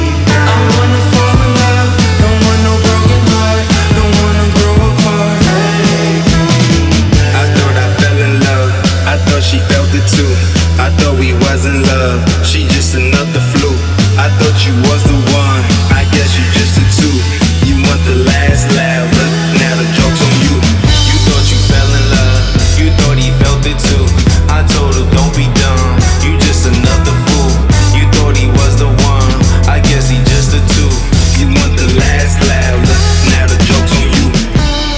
Rap Hip-Hop
Жанр: Хип-Хоп / Рэп